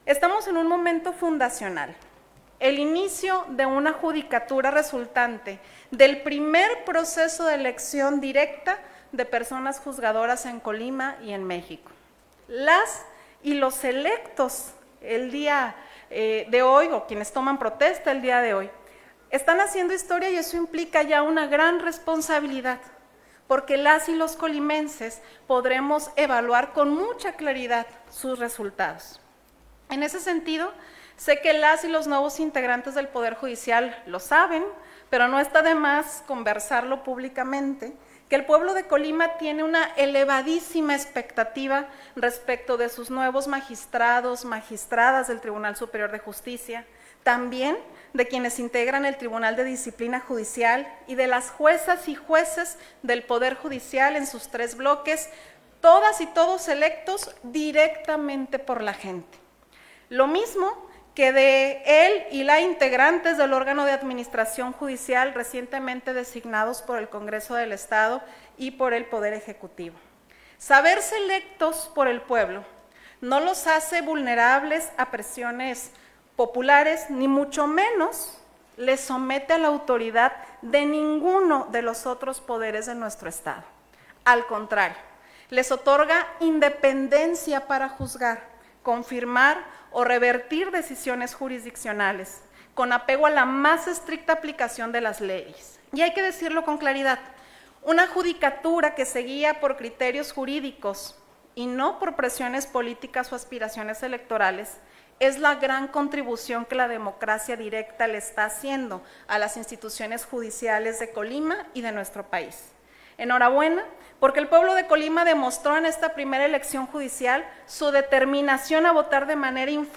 ++Gobernadora participó en la sesión solemne del Congreso del Estado, donde rindieron protesta quienes integran este Poder